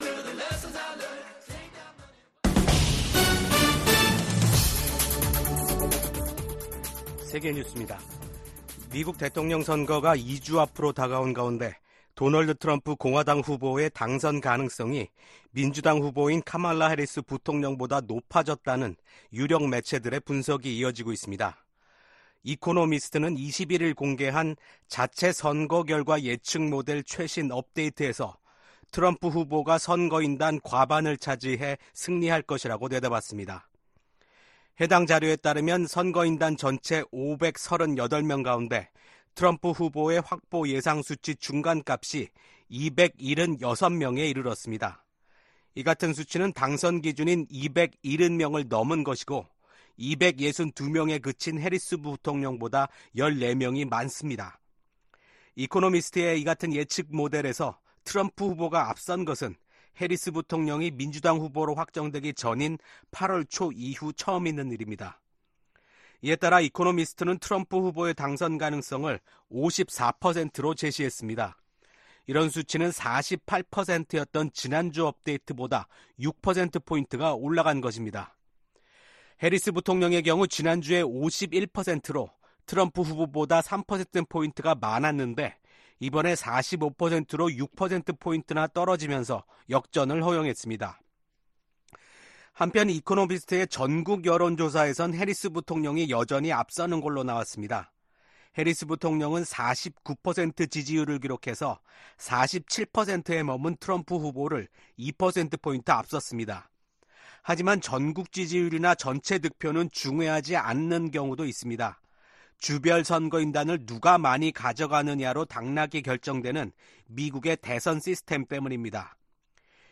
VOA 한국어 아침 뉴스 프로그램 '워싱턴 뉴스 광장' 2024년 10월 23일 방송입니다. 미국 정부가 북한의 러시아 파병은 러시아 대통령의 절박함과 고림감이 커지고 있다는 증거라고 지적했습니다. 군축과 국제안보 문제를 다루는 유엔 총회 제1위원회 회의에서 북한의 핵∙미사일 프로그램 개발에 대한 우려와 규탄이 연일 제기되고 있습니다.